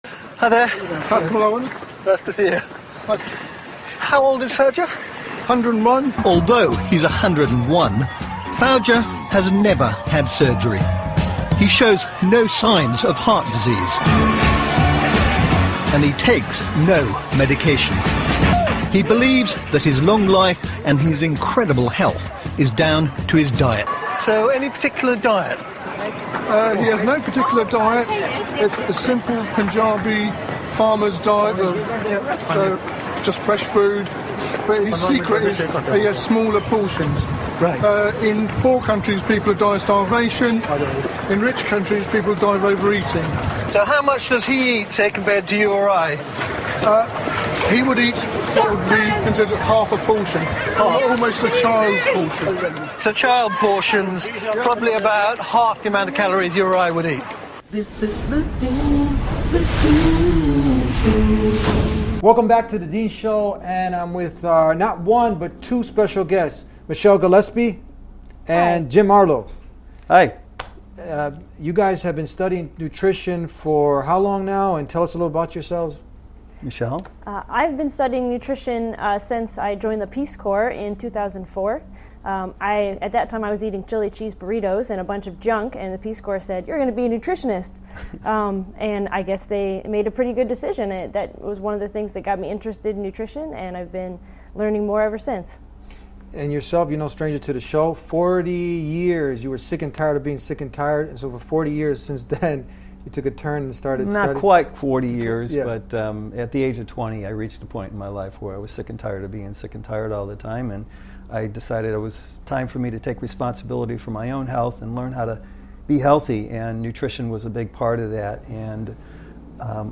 The conversation urges viewers to prioritize real and natural foods to attain vibrant health and vitality. Furthermore, the episode aims to educate individuals on the potential dangers of ingesting fake or chemically treated foods.